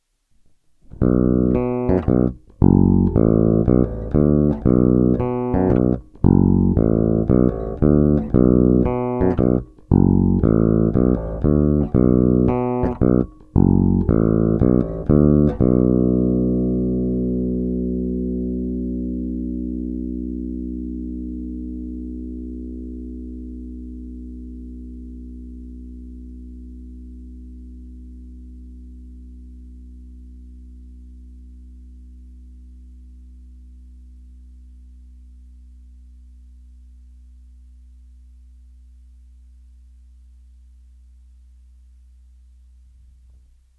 Zvuk má očekávatelně moderní charakter, je pevný, konkrétní, vrčí, má ty správné středy důležité pro prosazení se v kapele.
Není-li uvedeno jinak, následující nahrávky jsou provedeny rovnou do zvukové karty a s korekcemi na středu a dále jen normalizovány, tedy ponechány bez postprocesingových úprav.
Snímač u kobylky